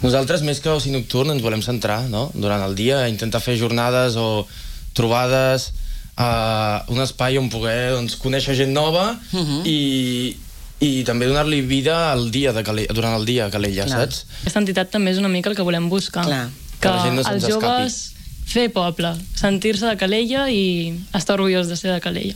Aquest dilluns, una representació de l’associació ha passat pel matinal de RCT per explicar els seus projectes entre els que hi ha crear una marca que els identifiqui.